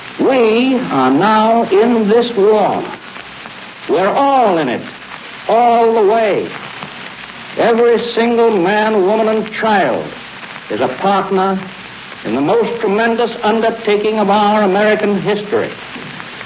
President Franklin Delano Roosevelt, in his December 9th, 1941 Fireside Chat broadcast to the American people over radio, stated that the